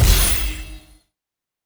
sfx_gacha 01.wav